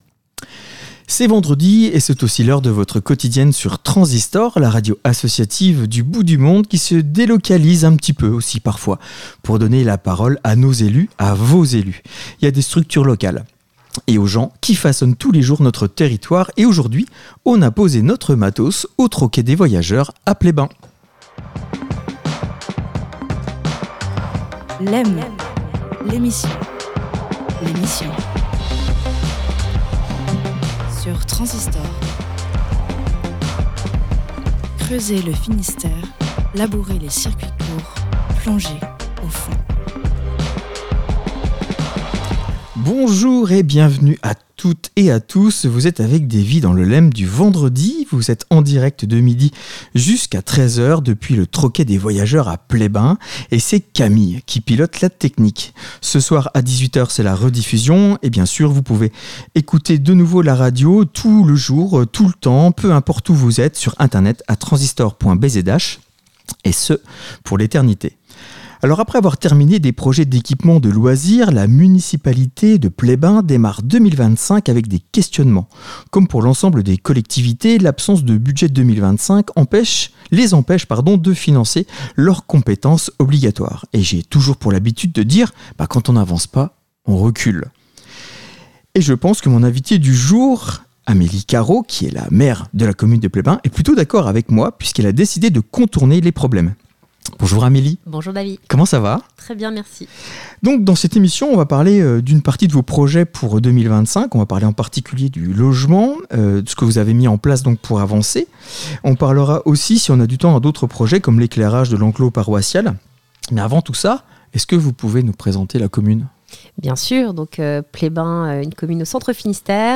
Une fois par mois le Lem se délocalise pour plus d'informations locales. On a posé la mallette du studio portable au Troquet des voyageurs à Pleyben.